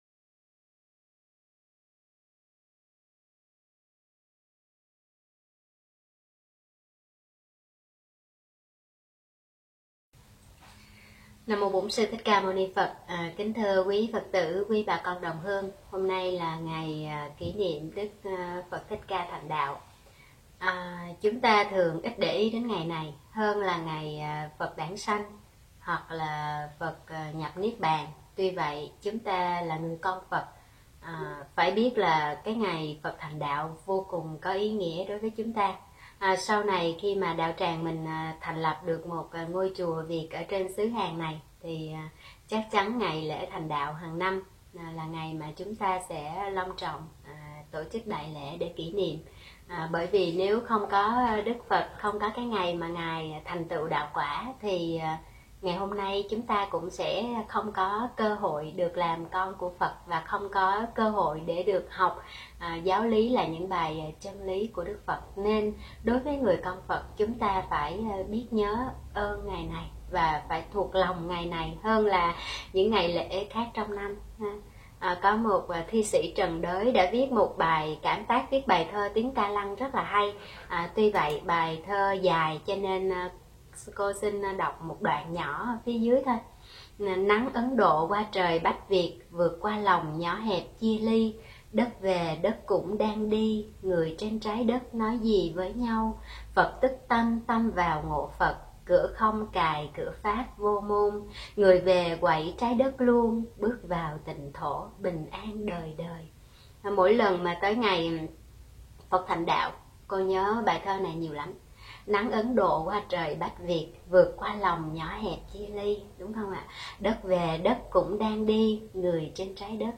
Thuyết pháp Ý nghĩa ngày Phật thành đạo